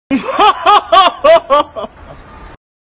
金馆长鬼畜笑声音效_人物音效音效配乐_免费素材下载_提案神器